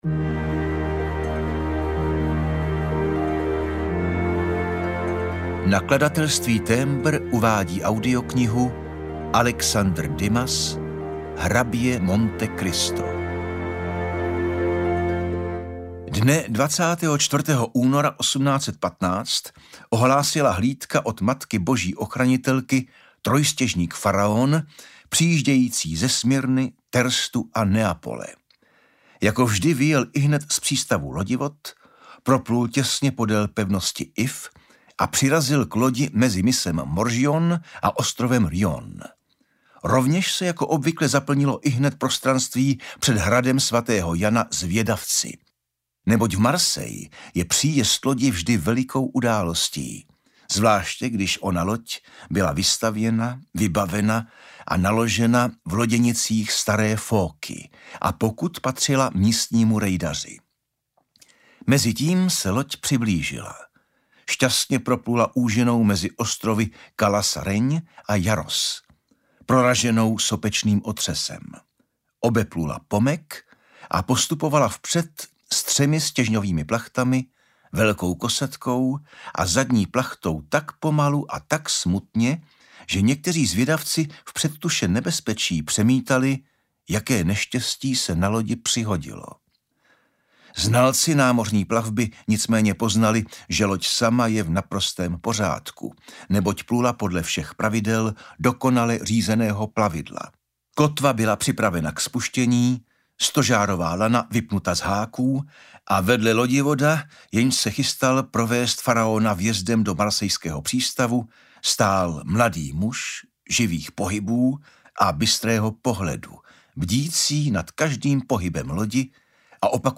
Hrabě Monte Cristo audiokniha
Ukázka z knihy
hrabe-monte-cristo-audiokniha